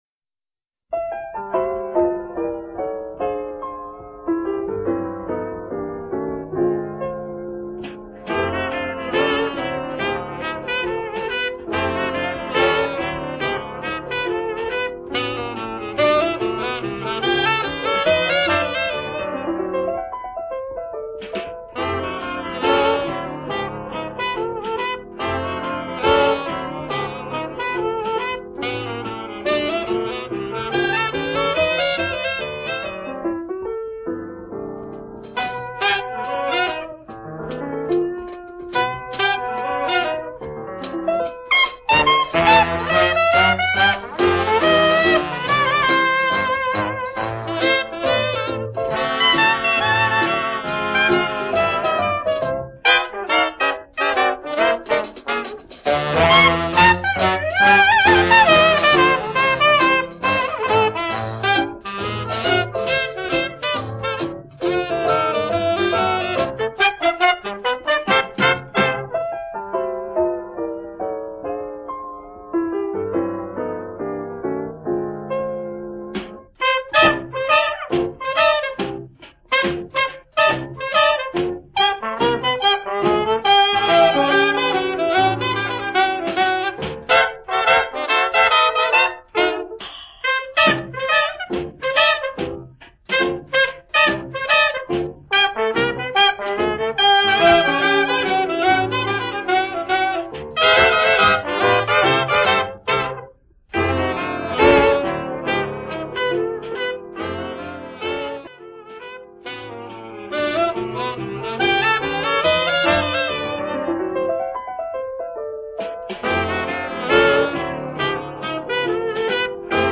Voicing: 6 Horns/Rhy